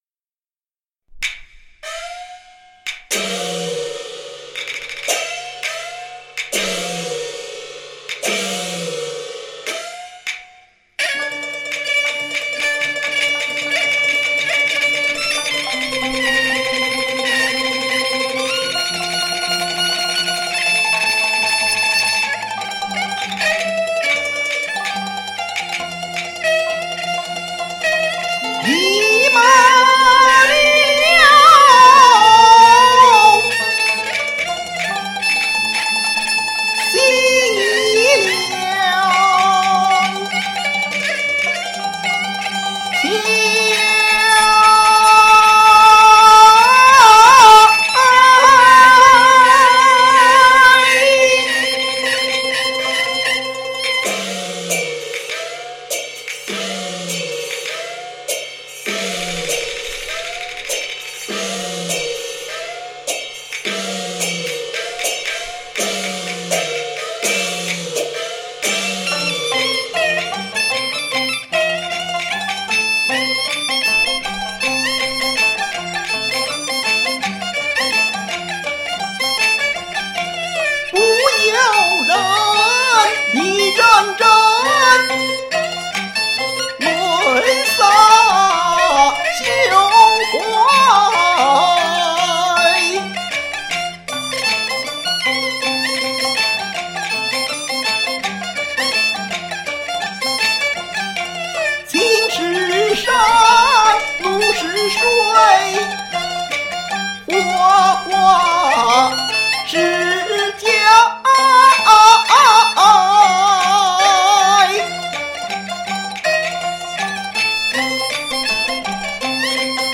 过节唱大戏，凑个热闹，有点千呼万唤始出来的意思，实在不好意思。